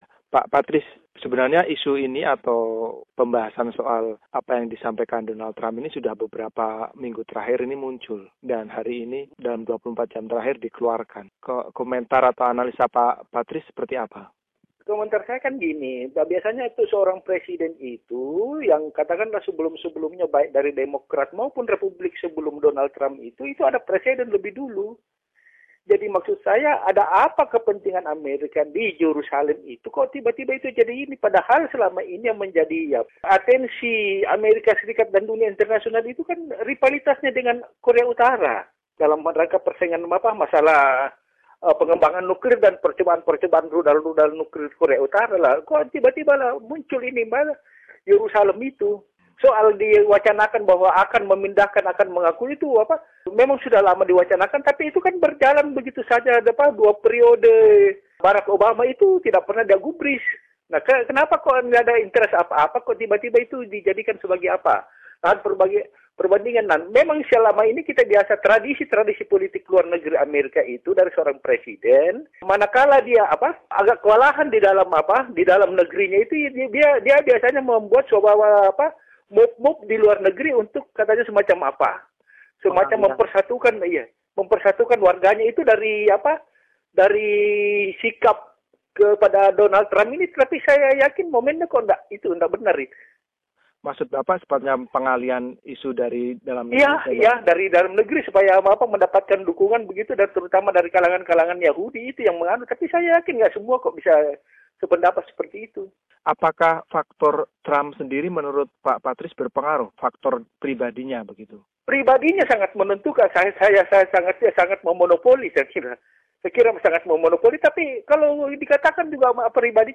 mewawancarai